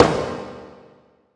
TEC敲击乐 " hamr小号鼓的旋转声
描述：修改过的锤子的声音。
标签： 冲动 口音 砰的一声 锣鼓 小军
声道立体声